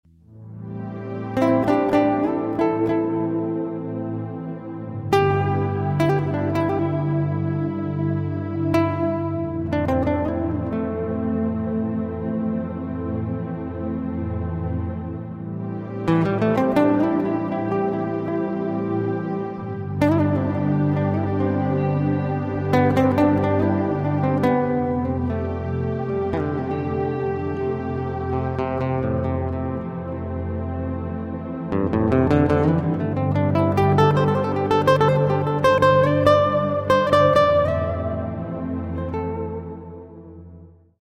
(Guitar Music - Only on CD)